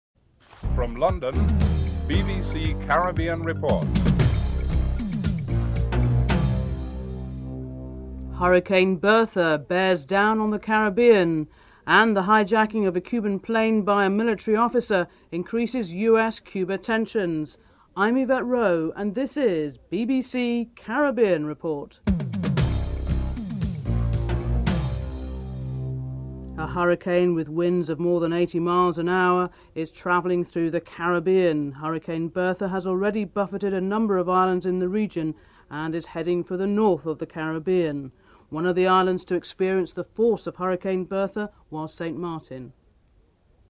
Headlines (00:00-00:25)
The hijacking of a Cuban plane by a military officer increases US Cuba tension. President of the Cuban National Assembly Ricardo Alarcon is interviewed (05:28-07:07)